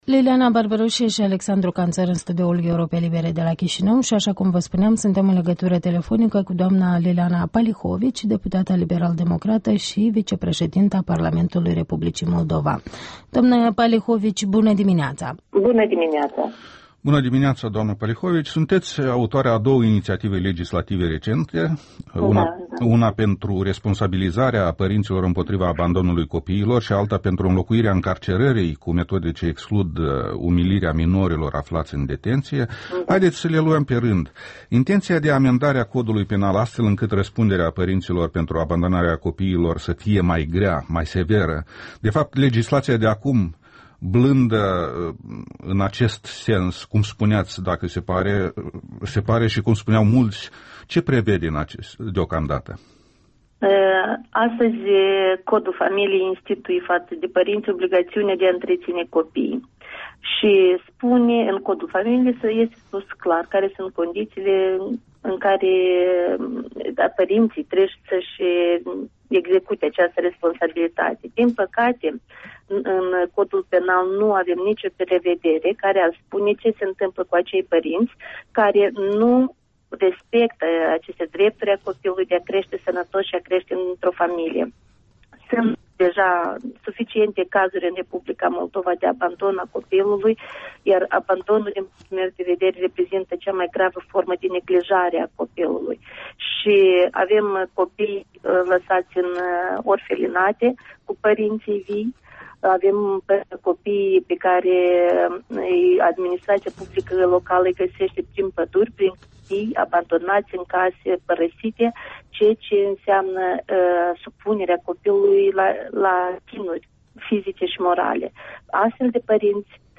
Interviul matinal la Europa Liberă: cu vicepreşedinta Parlamentului, Liliana Palihovici